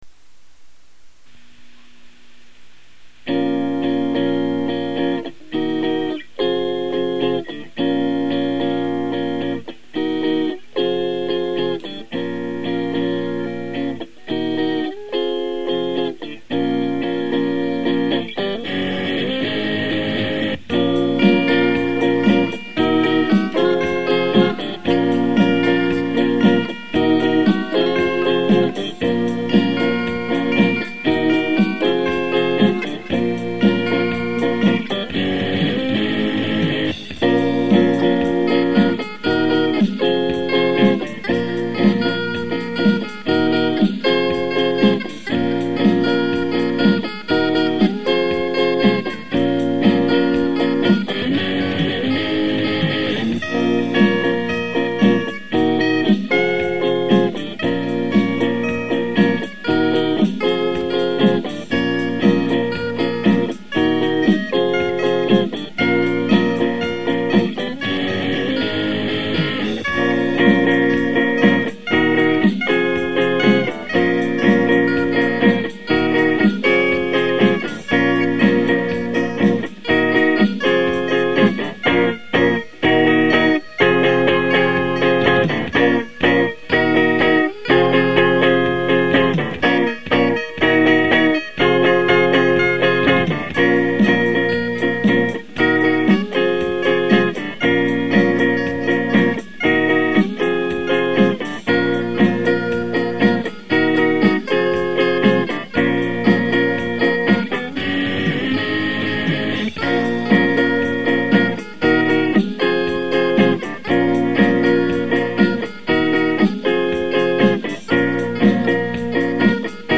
Description A Smiths-inspired instrumental.
guitar, drums